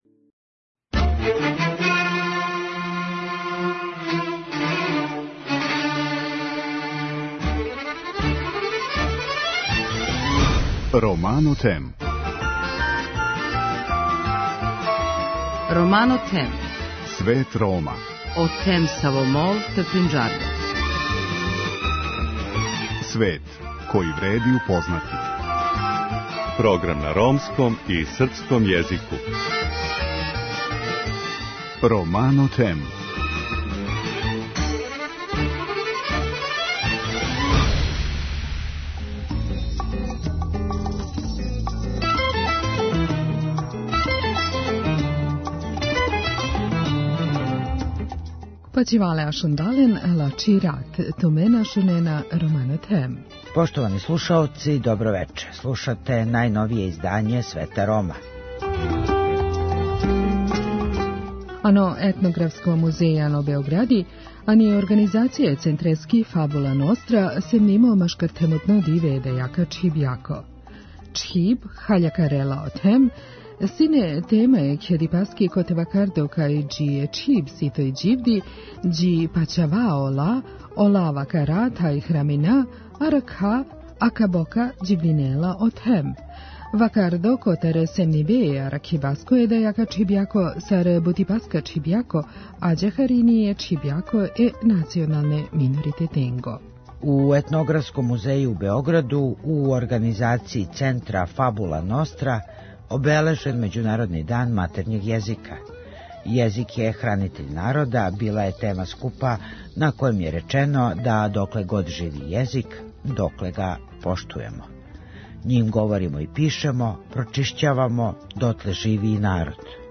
Емисија свакодневно доноси најважније вести из земље и света на ромском и српском језику.